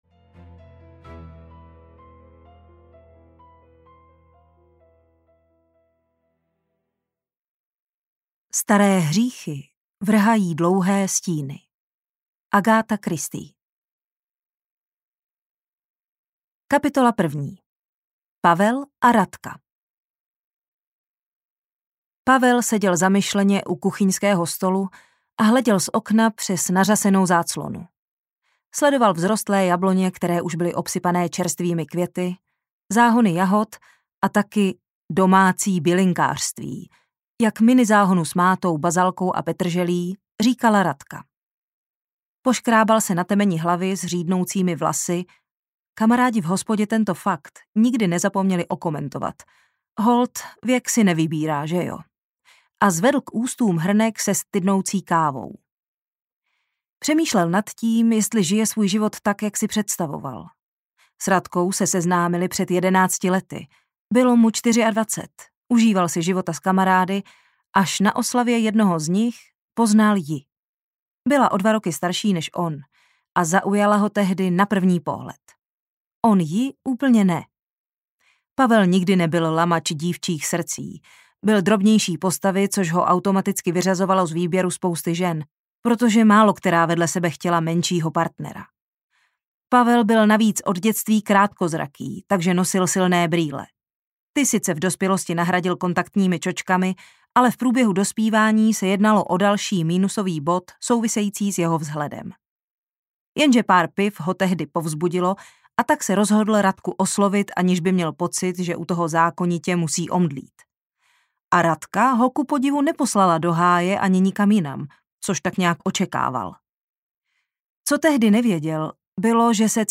Svědomí audiokniha
Ukázka z knihy
• InterpretZuzana Kajnarová